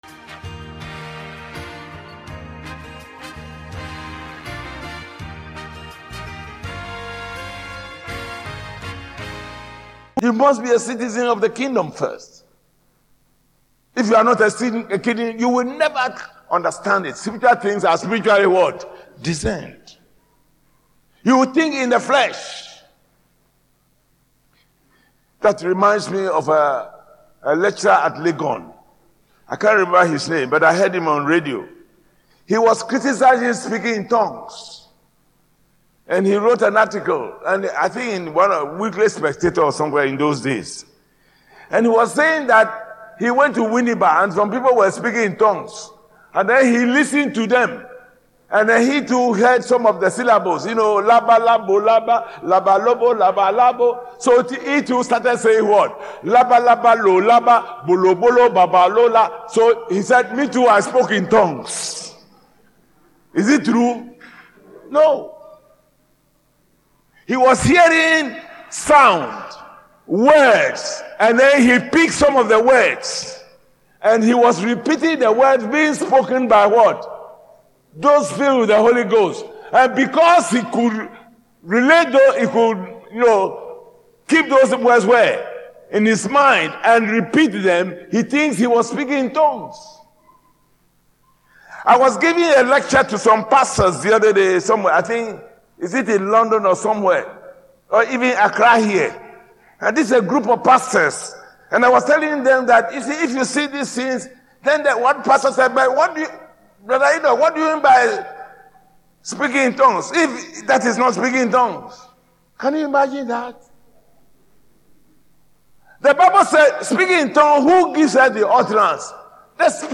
October 23, 2025 The Kingdom Gate Series: Audio Sermon SERMON TITLE: The Kingdom Gate .